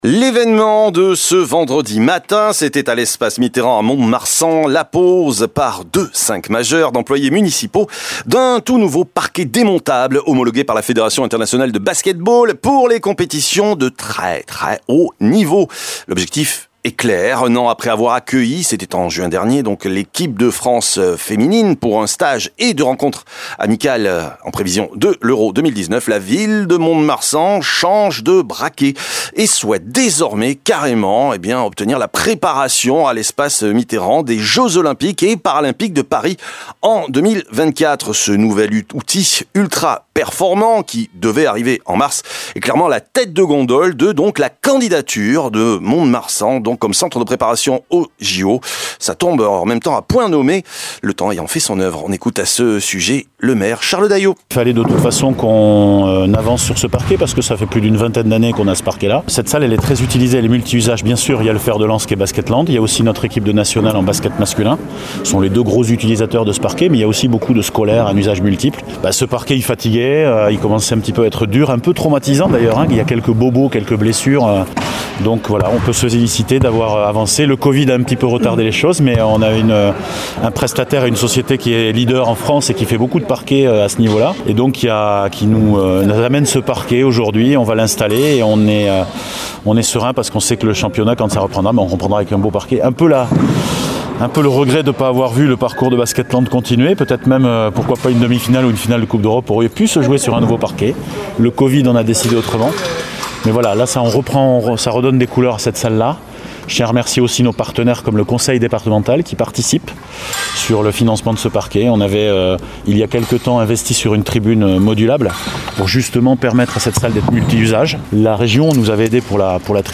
ITV